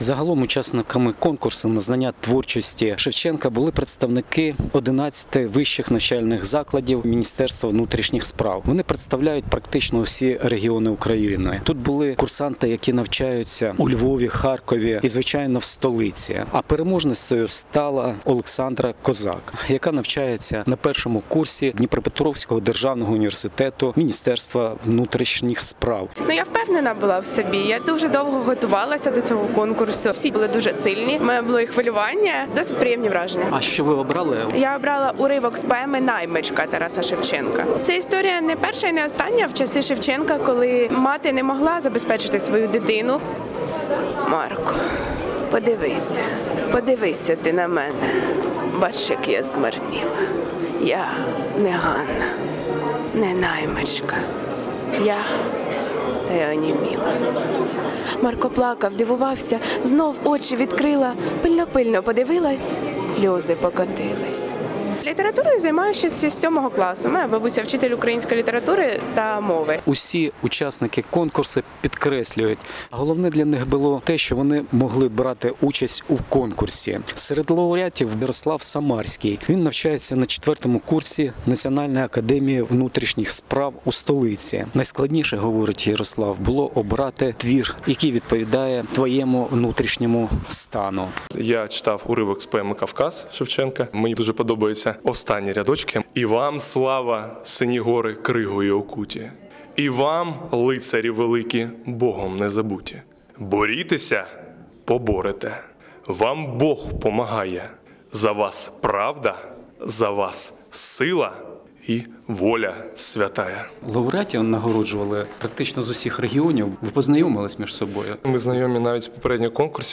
Шевченківські читання з нагоди 204-ї річниці від дня народження Тараса Шевченка відбулися сьогодні у Києві серед курсантів навчальних закладів Міністерства внутрішніх справ.